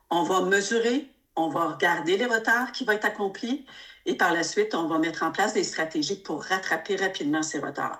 Une rencontre avait lieu mercredi de Santé Québec avec les médias pour donner les grandes lignes de son installation. Présente lors de celle-ci, la présidente-directrice générale du CIUSSS MCQ, Nathalie Petitclerc, a vaguement énuméré leur stratégie pour récupérer le retard que devrait causer ce déploiement.